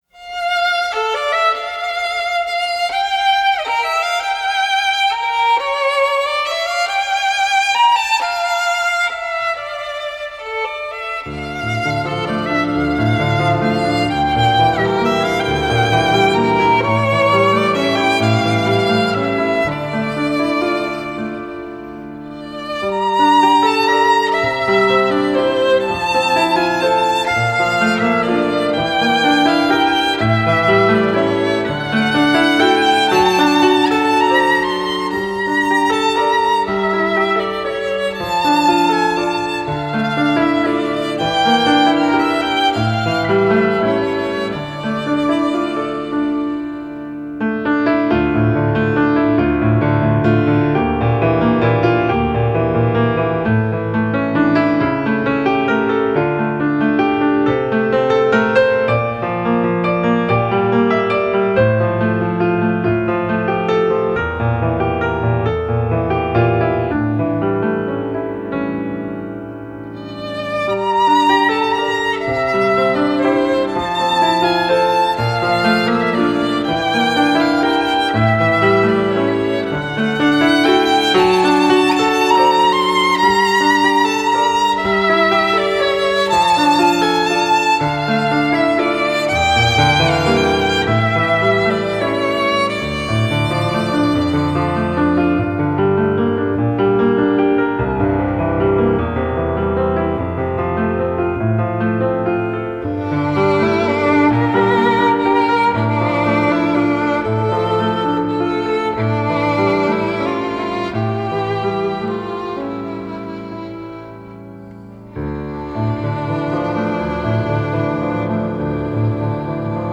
موسیقی بیکلام ویولن پیانو آرامش بخش